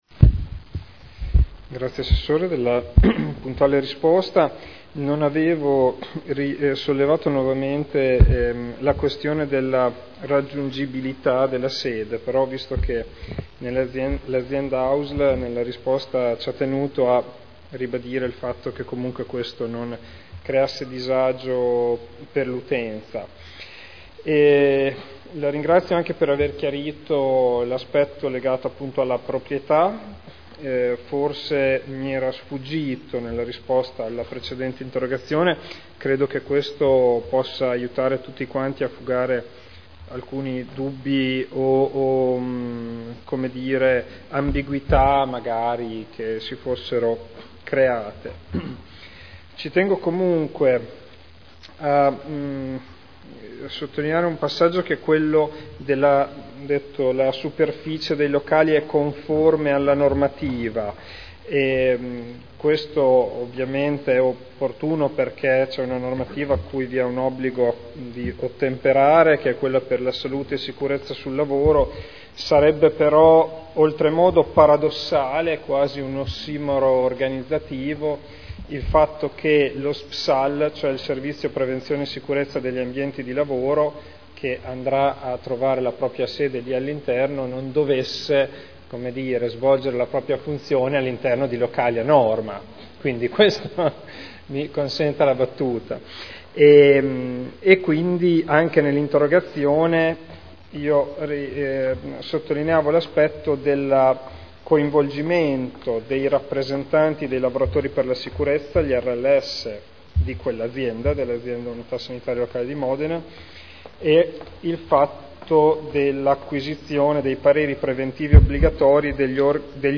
Conclude interrogazione urgente del consigliere Ricci (Sinistra per Modena) avente per oggetto: “Nuova sede DSP AUSL MO”